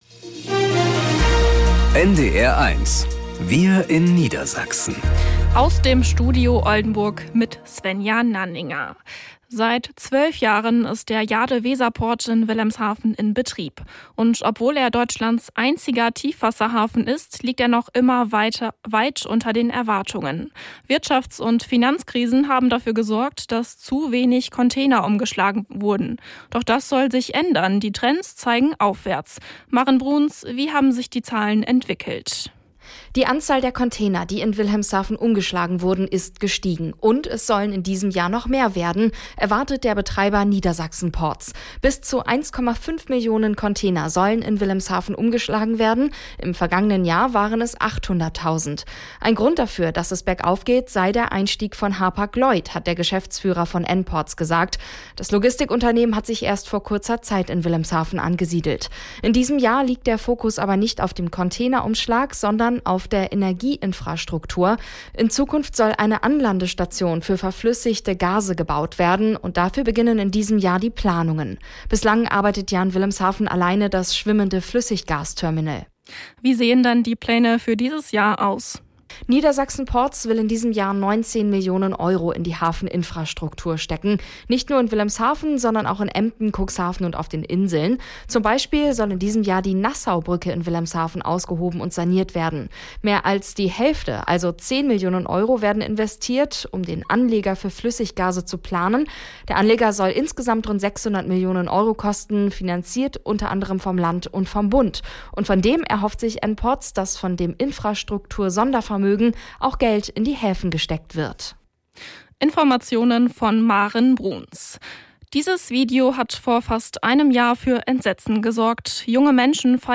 … continue reading 3 Episoden # Tägliche Nachrichten # Nachrichten # NDR 1 Niedersachsen